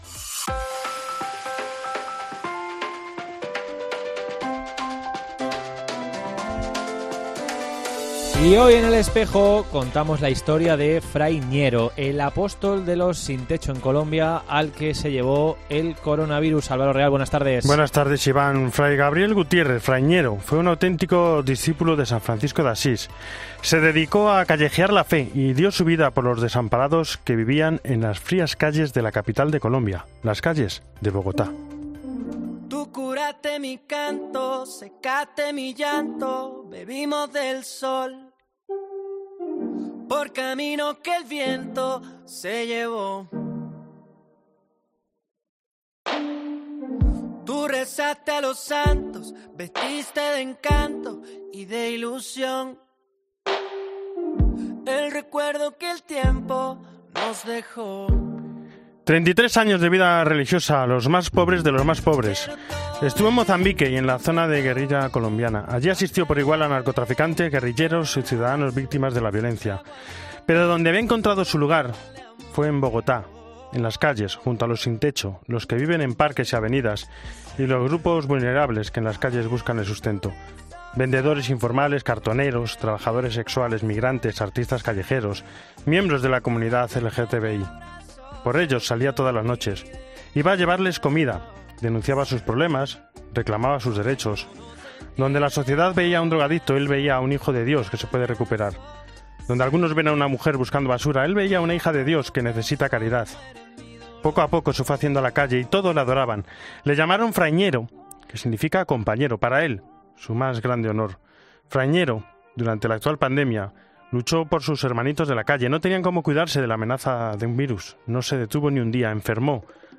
entrevista sobre el Sábado Santo y la Vigilia Pascual